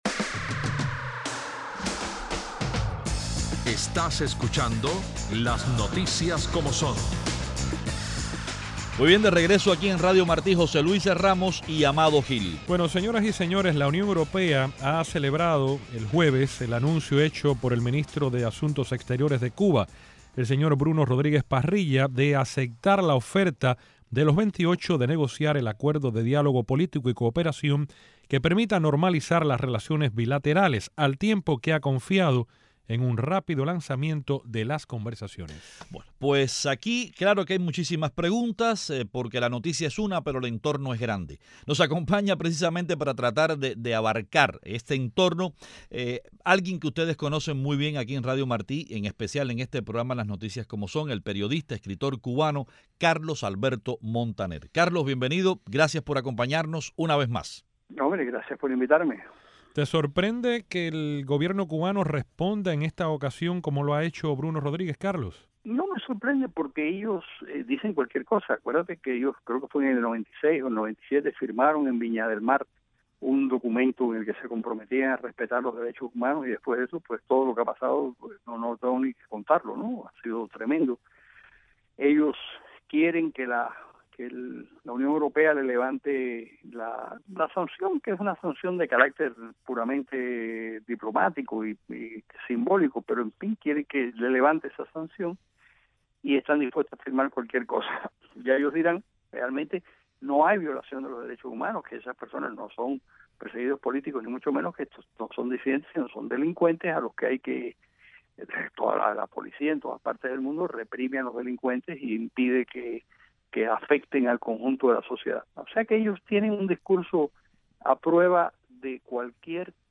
Cuba aceptó con satisfacción la propuesta de la Unión Europea de iniciar negociaciones de un acuerdo para normalizar las relaciones mutuas, dijo el canciller Bruno Rodríguez en una conferencia de prensa en La Habana. Nuestro invitado es el periodista y escritor cubano, Carlos Alberto Montaner.